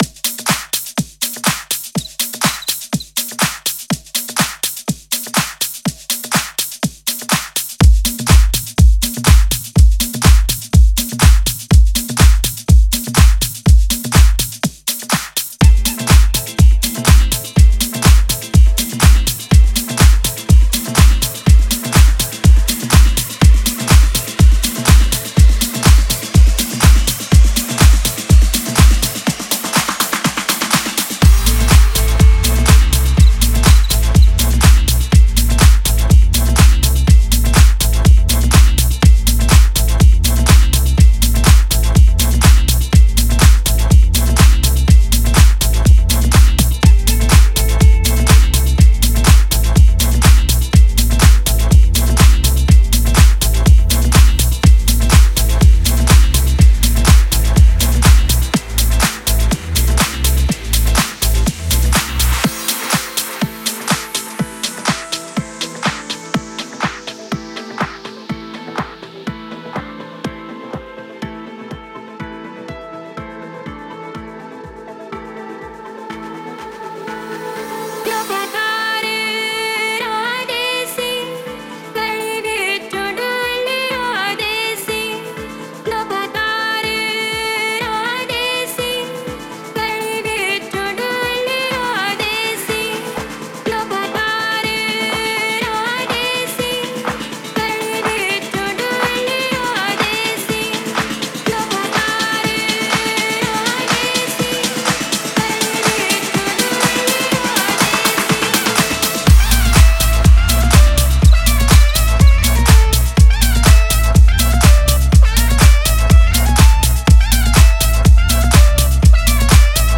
это энергичный трек в жанре электронной танцевальной музыки